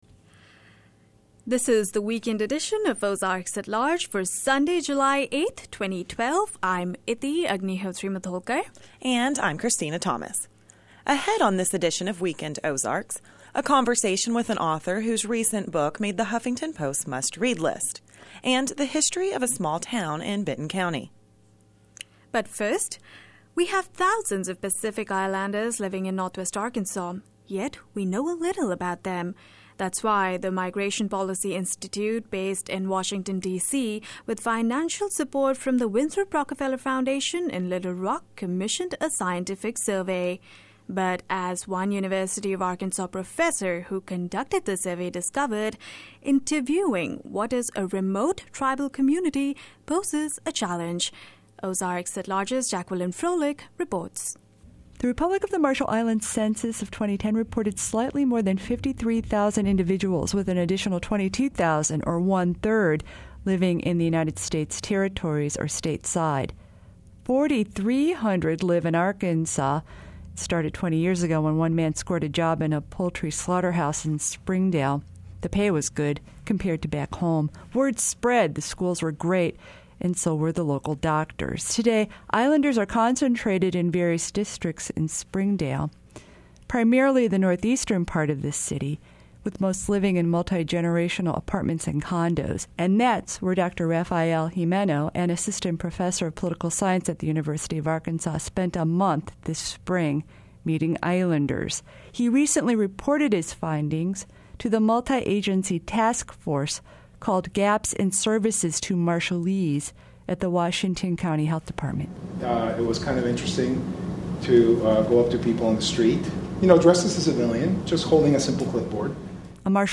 Audio: 7-8-12.mp3 On this weekend edition of Ozarks at Large, a conversation with an author whose recent book is on the Huffington Post's summer must-read list; and learning more about the history of a small town in Benton County.